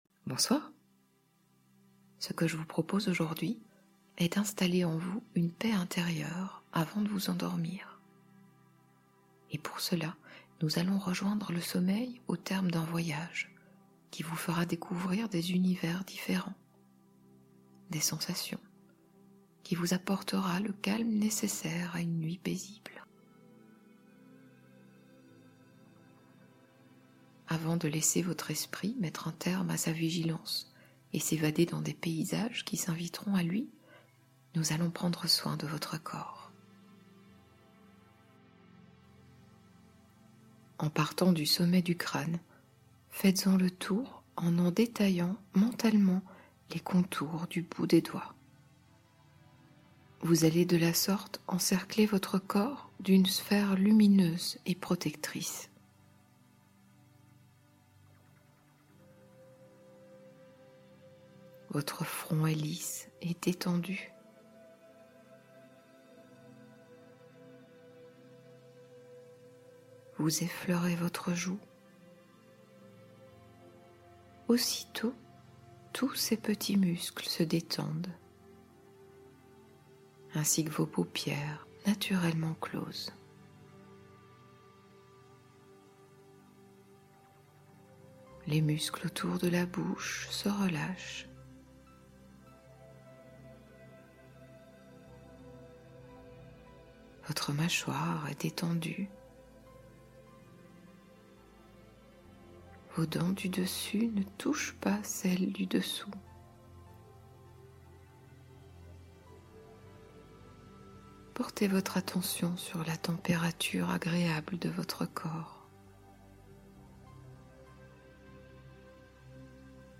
Escale Nocturne : Hypnose pour s'endormir sereinement en voyage